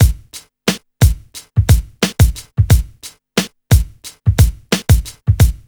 RNB89BEAT2-L.wav